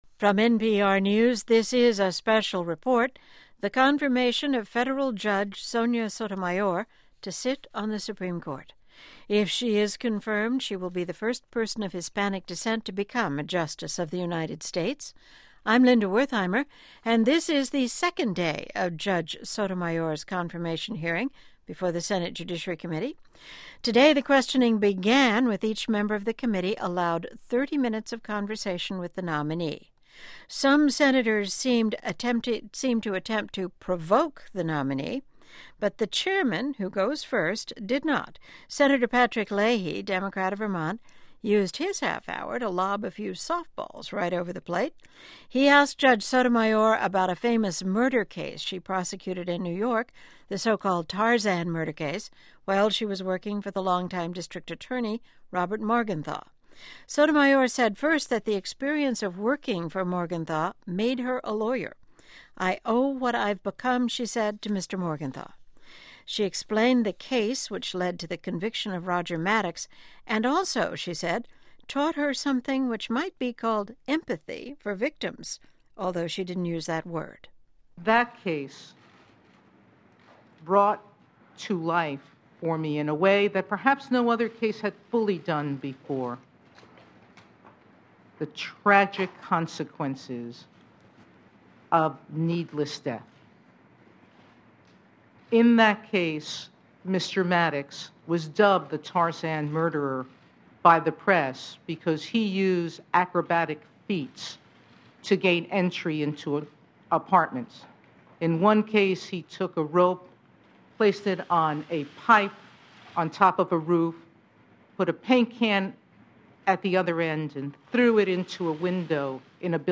The Supreme Court nominee remained unflappable during a barrage of aggressive and persistent questions from Senate Republicans.